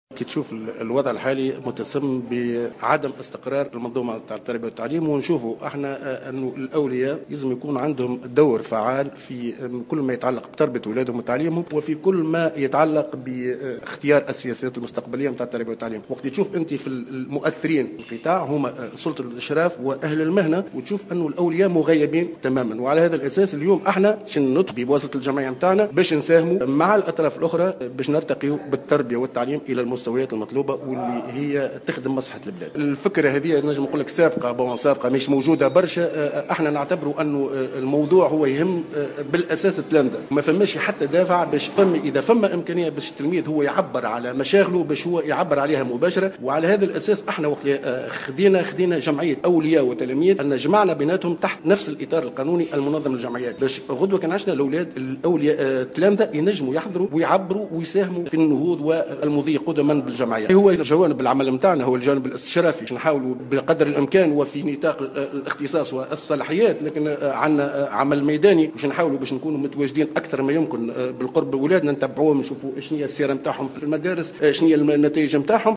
تم اليوم السبت 17 أكتوبر 2015 خلال ندوة صحفية بتونس العاصمة الإعلان الرسمي عن تأسيس الجمعية التونسية للأولياء والتلاميذ.